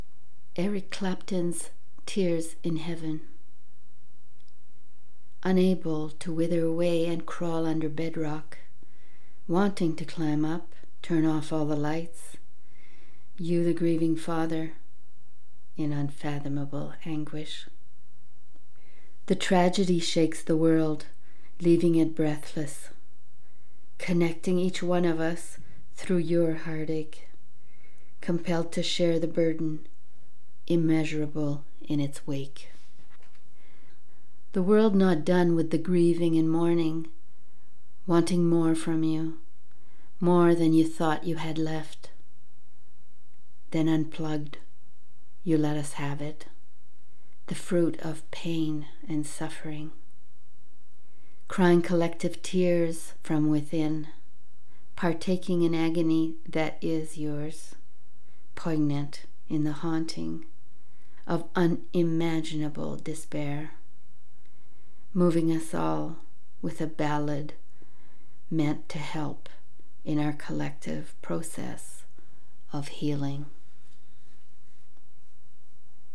childdeathgriefhurtlossfree verse